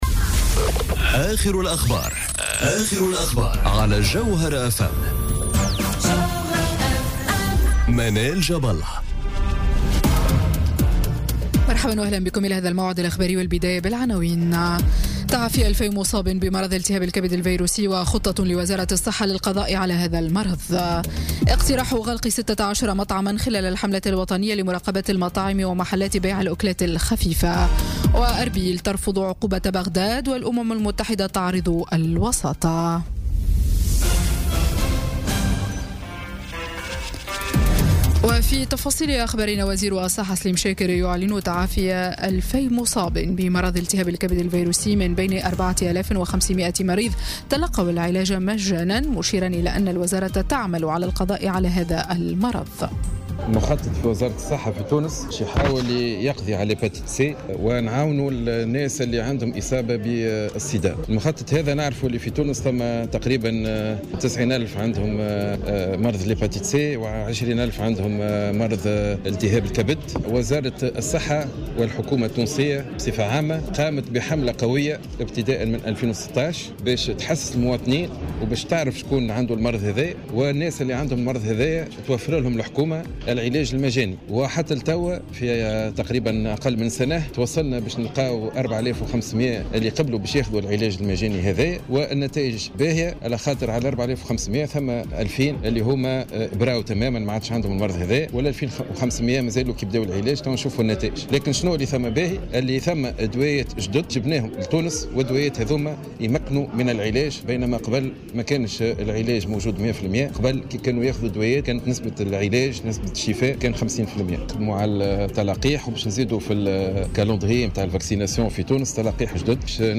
نشرة أخبار منتصف الليل ليوم الجمعة 29 سبتمبر 2017